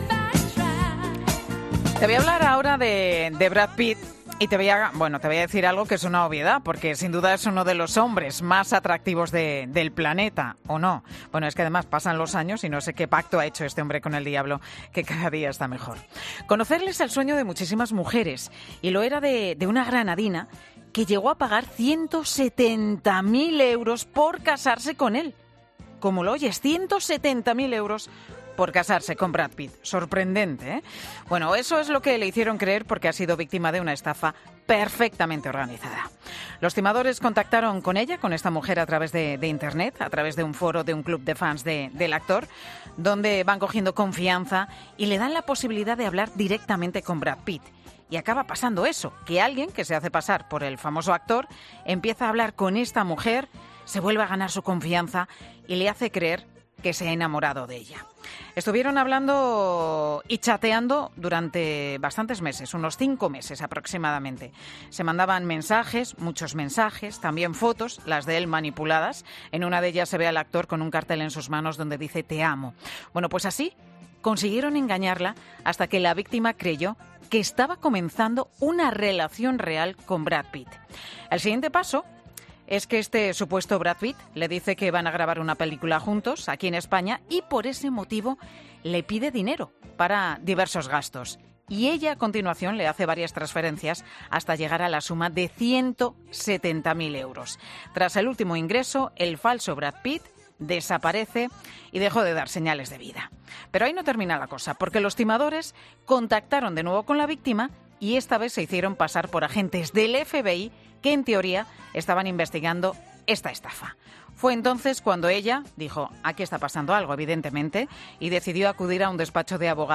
Escucha la entrevista al completo de un abogado explicando el caso de una granadina que ha sido estafada